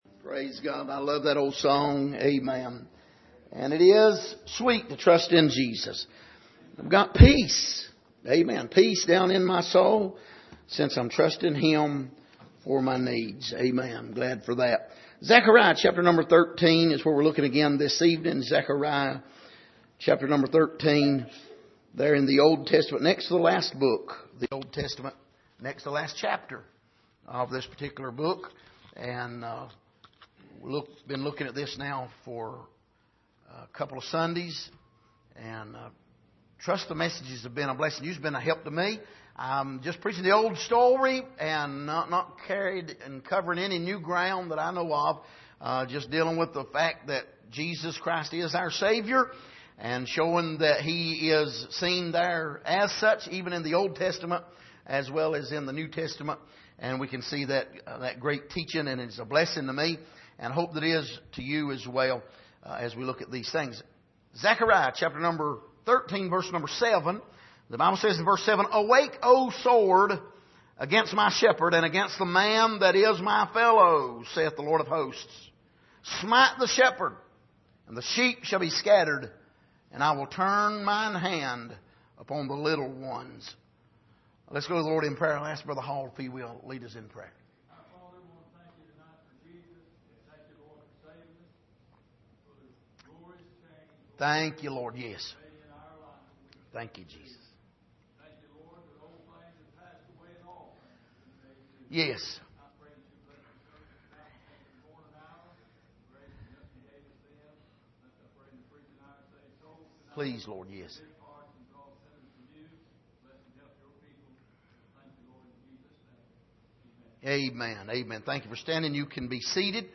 Passage: Zechariah 13:7 Service: Sunday Evening